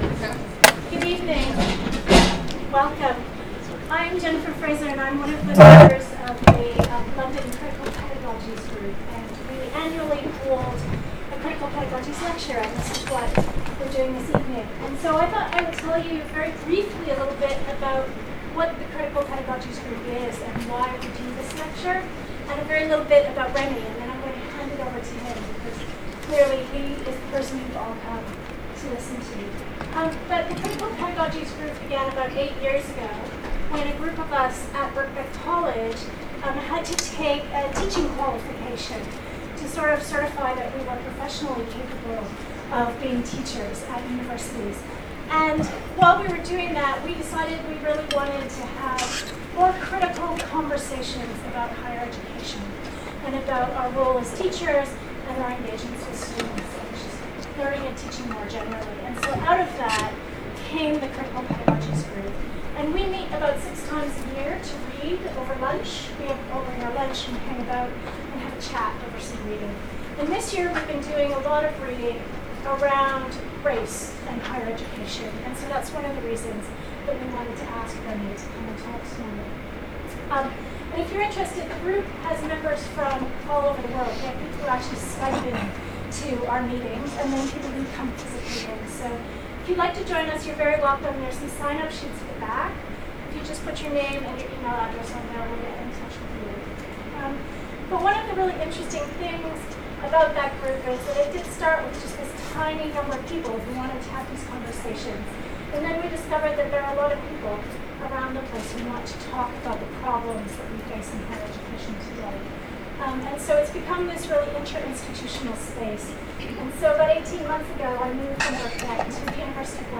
Annual Lecture 2018 ‘Institutionalised whiteness, racial microaggressions and Black bodies out of place in Higher Education’
24 May 2018, hosted by the Critical Pedagogies Reading Group and the Centre for Teaching Innovation, University of Westminster
A podcast of this lecture is available.